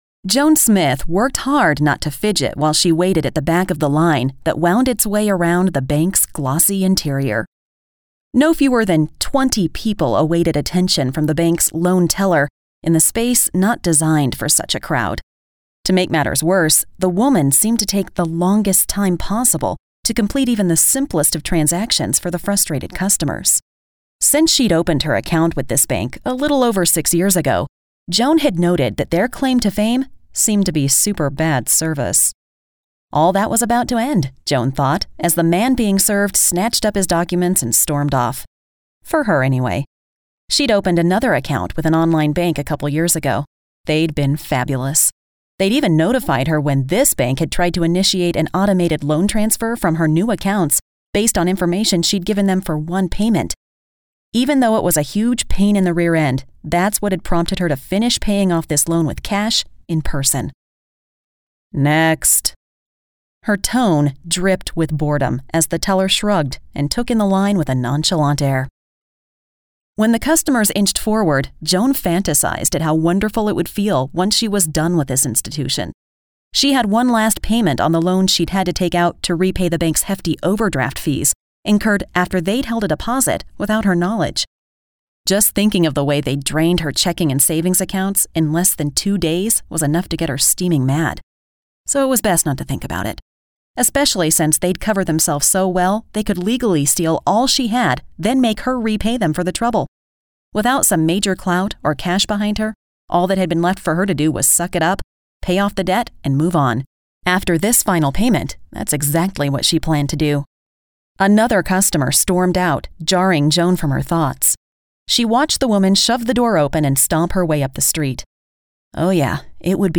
Female
English (North American)
Yng Adult (18-29), Adult (30-50)
Audiobooks
Female Voice Over Talent